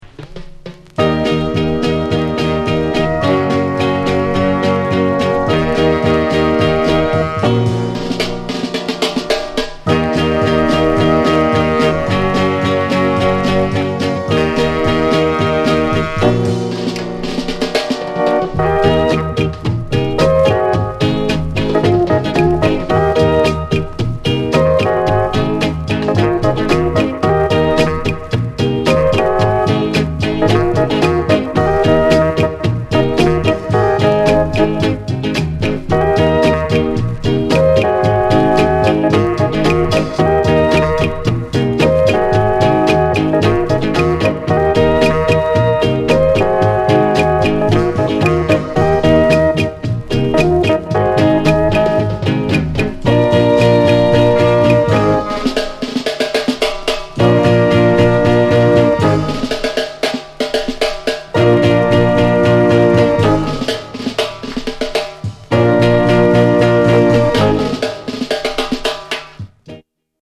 SOUND CONDITION